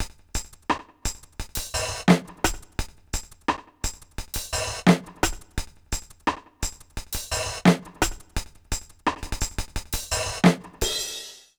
British REGGAE Loop 087BPM (NO KICK).wav